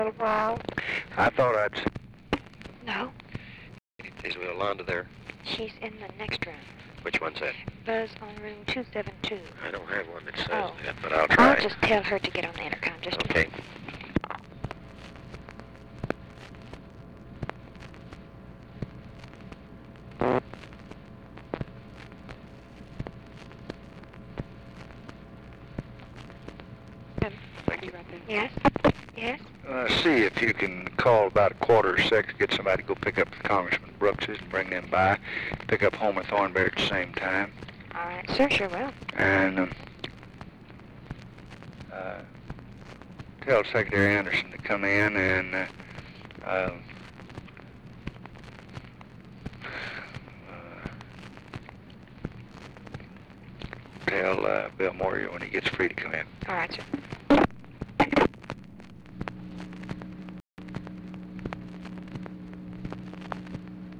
Conversation with OFFICE SECRETARY, November 24, 1963
Secret White House Tapes